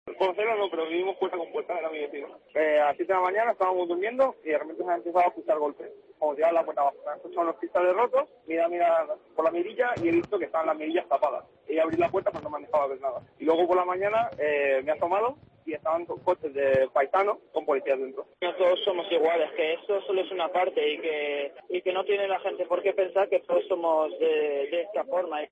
Escucha a varios vecinos del marroquí detenido en Palma de Mallorca por su vinculación con Daesh